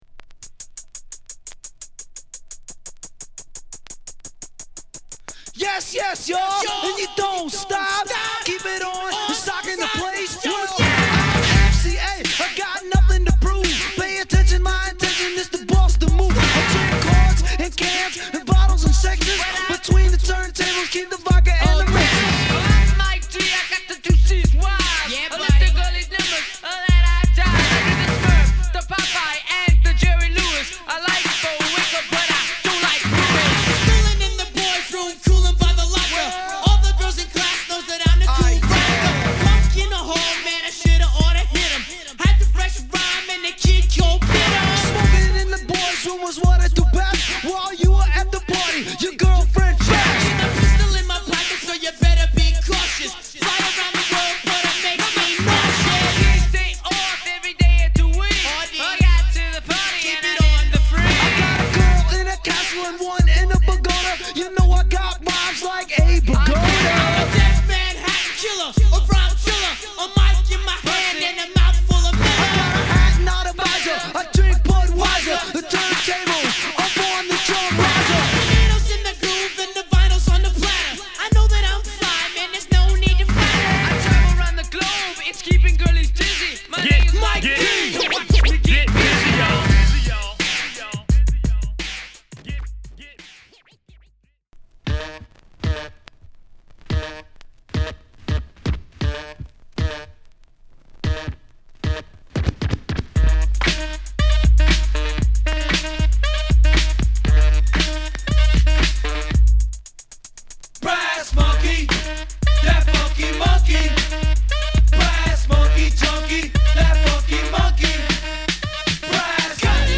オールドスクールにロックを混ぜてフレッシュに仕上げたスロウなマシーンドラムビートにテンション高いラップが乗る！
＊SideB盤面白いですがPlay良好です。